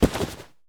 foley_combat_fight_grab_throw_10.wav